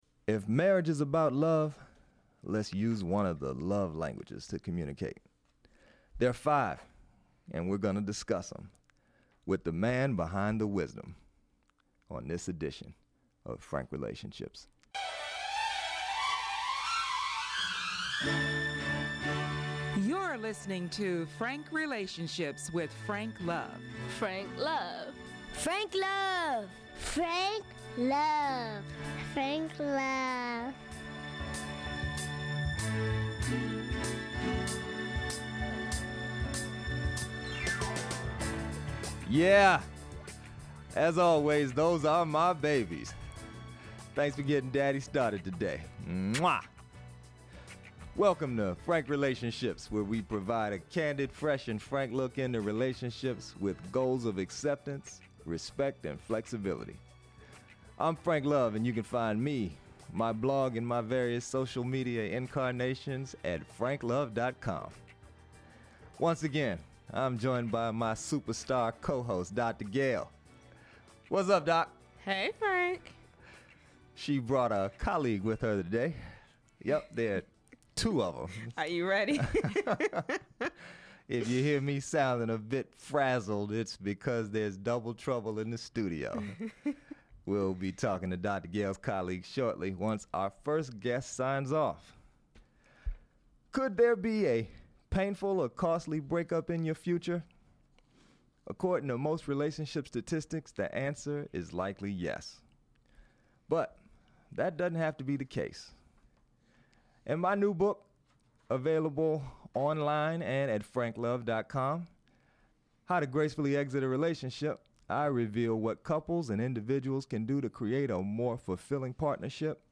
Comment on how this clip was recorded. If you hear me sounding a bit frazzled, it’s because there’s double trouble in the studio.